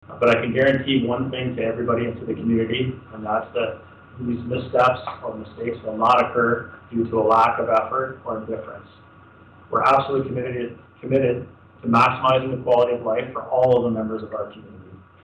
Deputy Chief Barry was sworn in by Mr. Justice Stephen Hunter at the new Belleville Police Service headquarters.
deputy-police-chief-barry.mp3